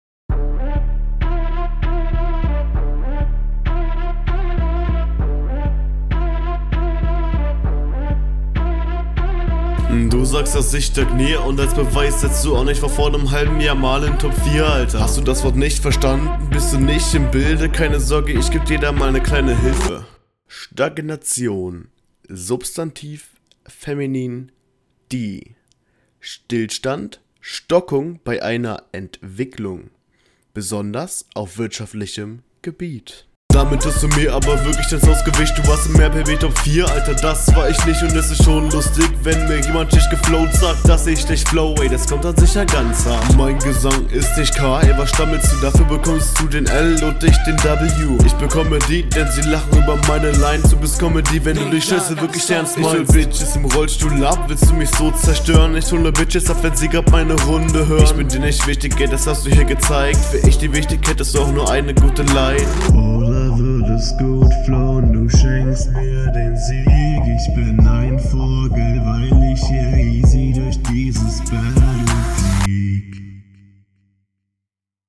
ja, also Intro klingt wieder iwie offbeat und die Idee mit dem Beatcut und dem …